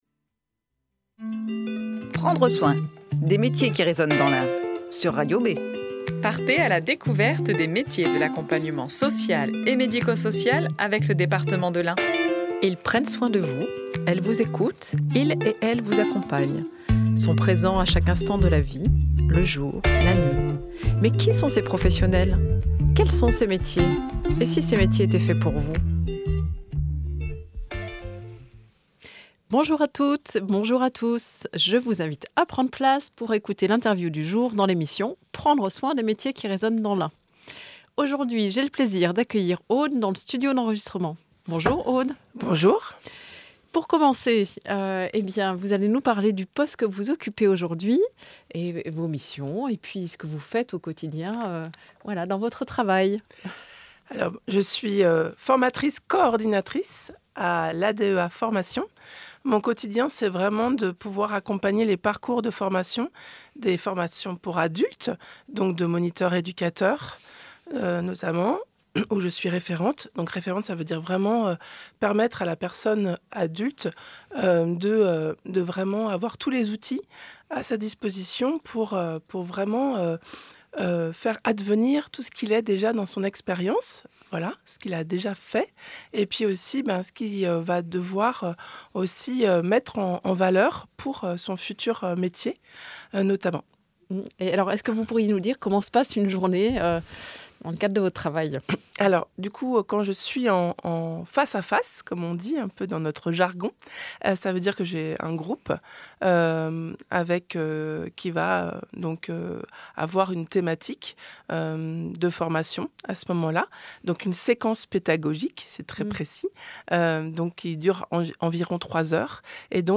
Tous les troisièmes vendredis du mois, retrouvez une "interview minute" avec un professionnel des métiers de l'accompagnement social et médico-social.